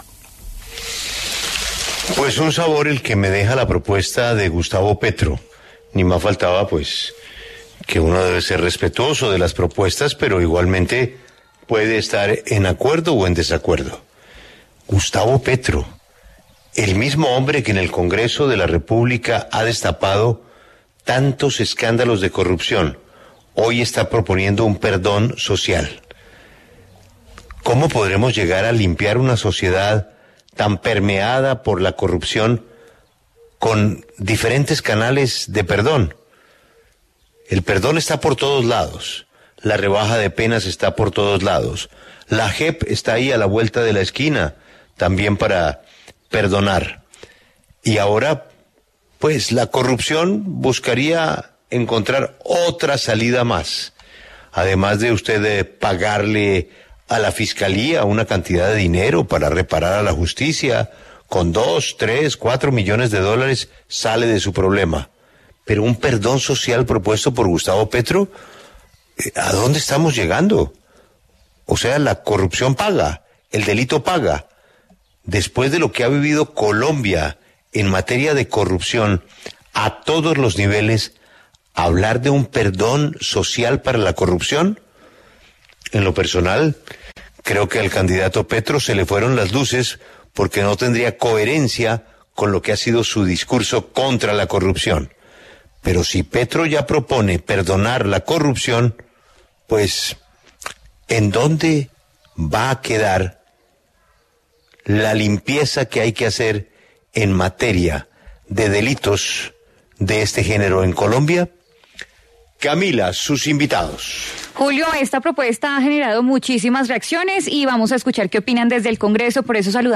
Julián Gallo, Ricardo Ferro y Guillermo García Realpe se pronunciaron en La W sobre la propuesta de “perdón social” del candidato presidencial Gustavo Petro.